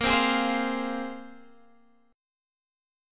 Bb7sus2 chord